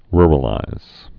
(rrə-līz)